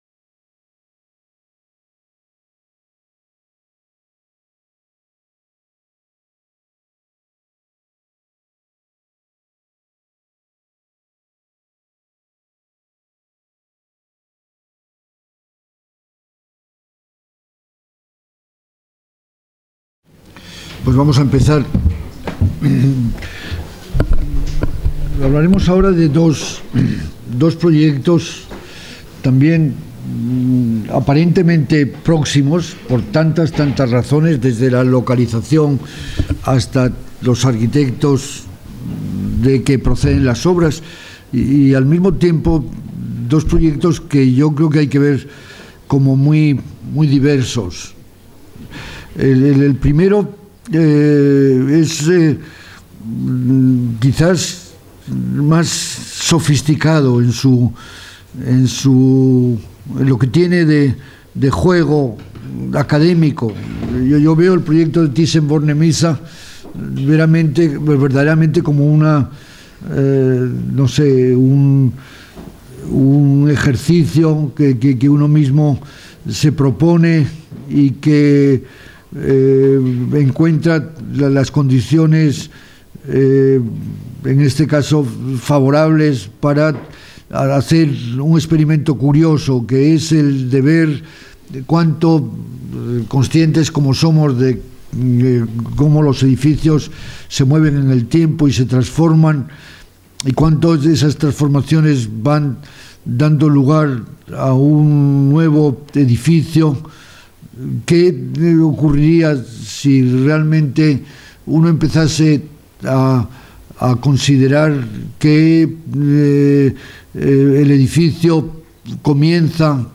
Conferència dins les 'Lliçons sobre arquitectura' organitzades per la Càtedra Ferrater Mora de Pensament Contemporani en el marc dels actes en motiu del seu 20è aniversari. Rafael Moneo parla en aquesta conferència de dos projectes arquitectònics: el Museo Thyssen-Bornemisza i el Museo del Prado  Tots els drets reservats Mostra el registre complet de l'element